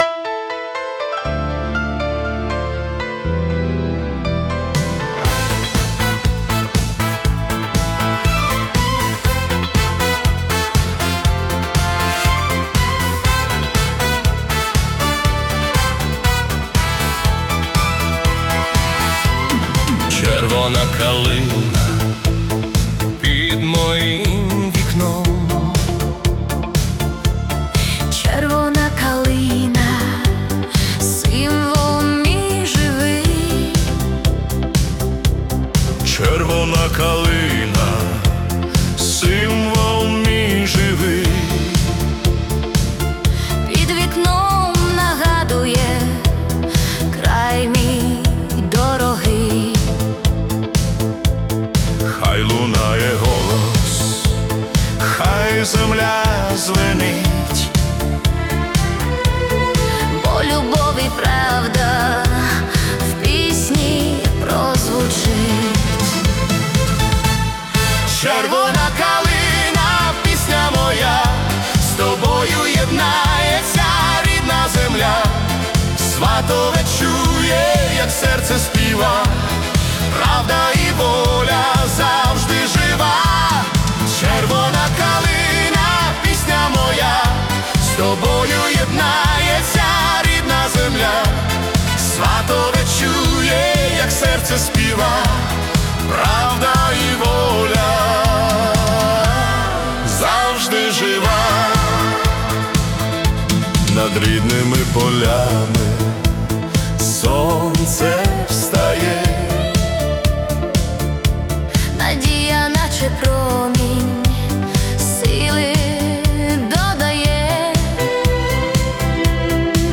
🎵 Жанр: Italo Disco / Patriotic